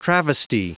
Prononciation du mot travesty en anglais (fichier audio)
Prononciation du mot : travesty